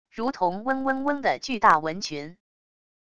如同嗡嗡嗡的巨大蚊群wav音频